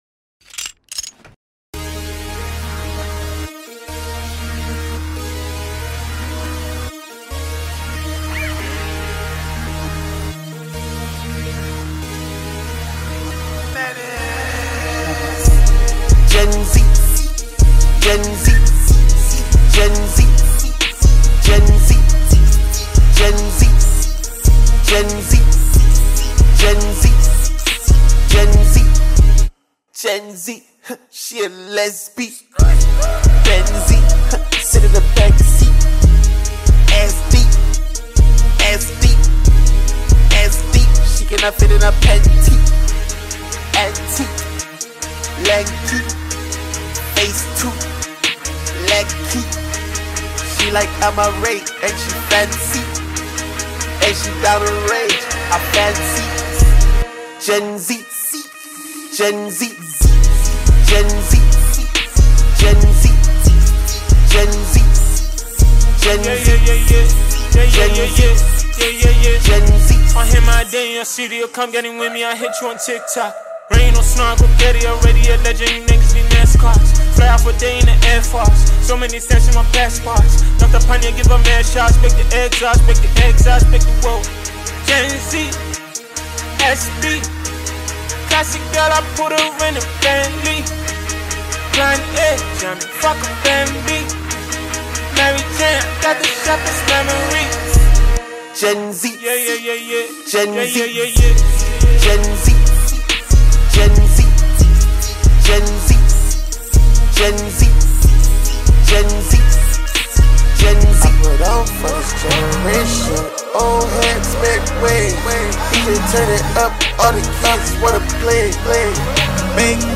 Ghana Music
with a rhythm that invites listeners to dance and celebrate.